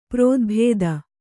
♪ prōdbhēda